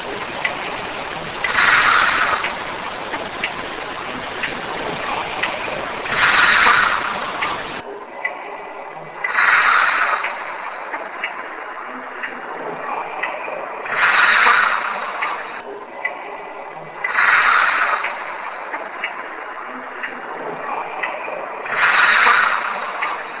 The clips below contain the original sound file and the cleaned versions.
Another Whisper - Our investigator's breathing can be heard during this clip, as can a far off whisper that sounds like a man and/or lady laughing. The first segment is the original followed by two cleaned versions of the clip. You can hear the laughing after the first breath. This clip was especially hard to analyze but know that the breathing is our investigator and not of ghostly origins.
anotherwhisper.wav